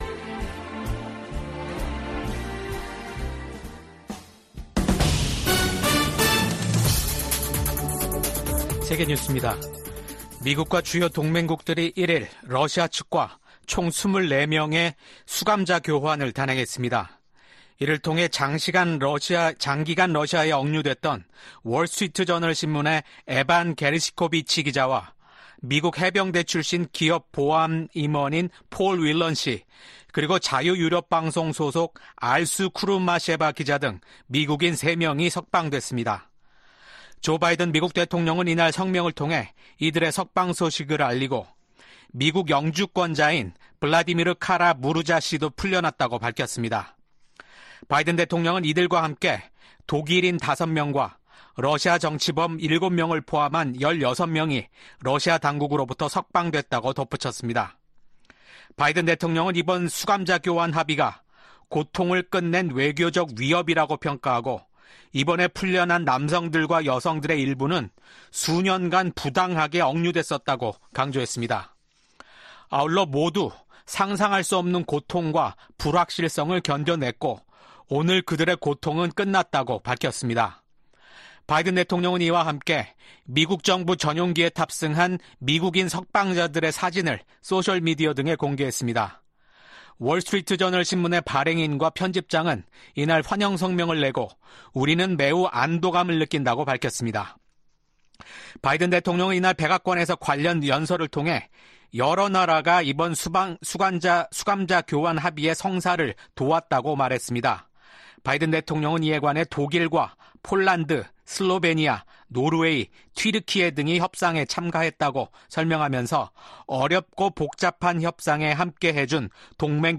VOA 한국어 아침 뉴스 프로그램 '워싱턴 뉴스 광장' 2024년 8월 2일 방송입니다. 올 하반기 미한 연합훈련인 을지프리덤실드(UFS)가 오는 19일부터 실시됩니다.